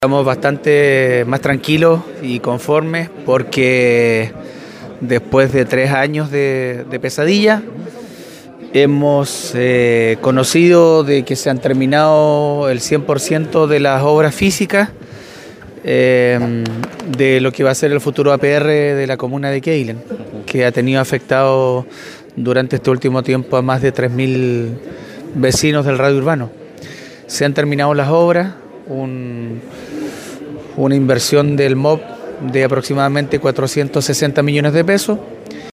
Así manifestó el alcalde de Quéilen, Marcos Vargas, quien entregó detalles del alcance que tendrá para los habitantes de la comuna.